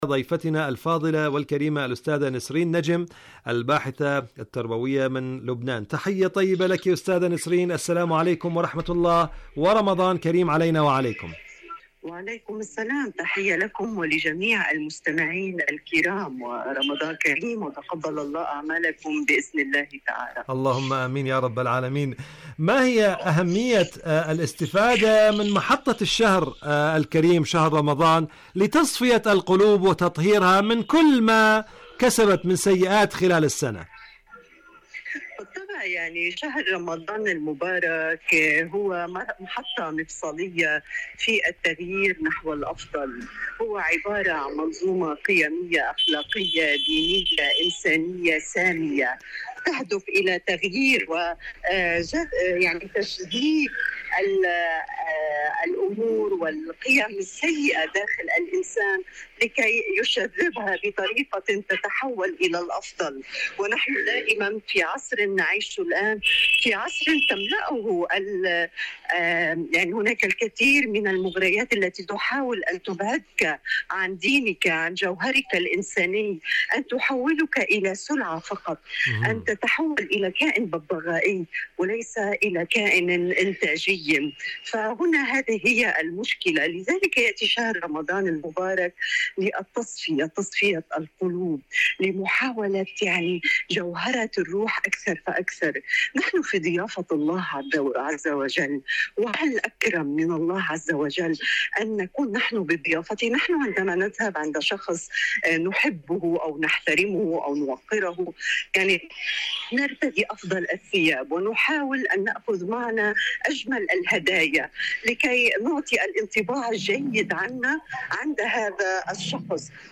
إذاعة طهران- معكم على الهواء: مقابلة إذاعية